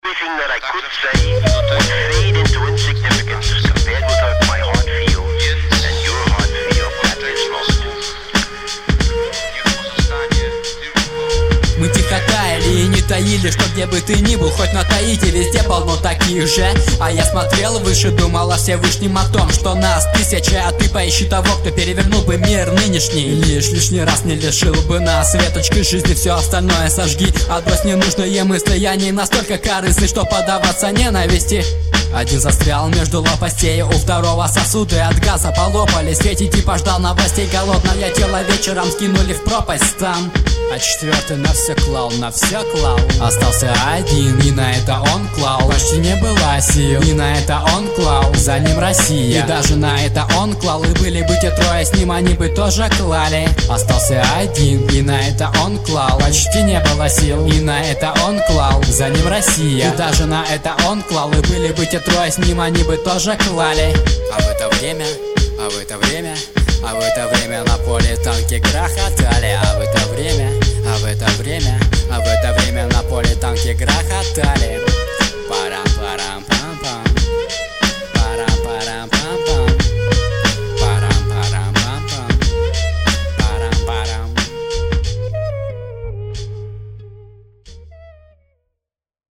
• Баттлы:, 2006-07 Хип-хоп
mp3,1397k] Рэп